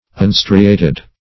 unstriated - definition of unstriated - synonyms, pronunciation, spelling from Free Dictionary Search Result for " unstriated" : The Collaborative International Dictionary of English v.0.48: Unstriated \Un*stri"a*ted\, a. (Nat.